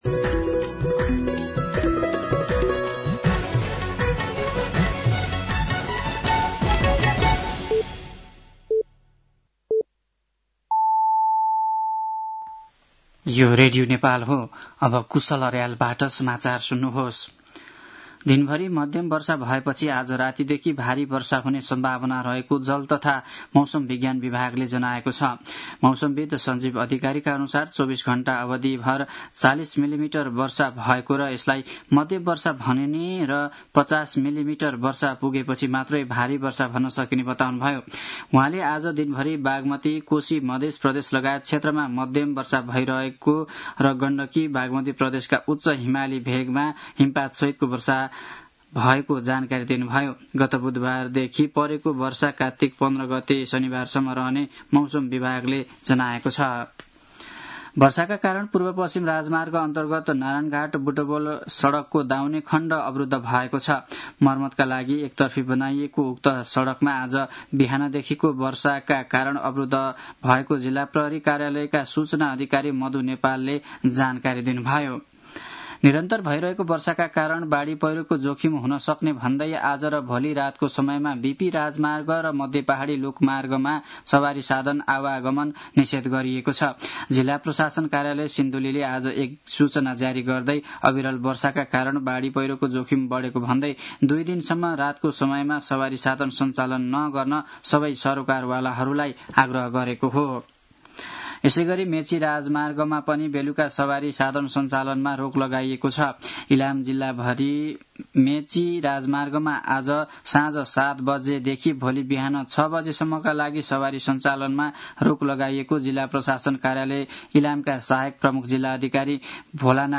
साँझ ५ बजेको नेपाली समाचार : १३ कार्तिक , २०८२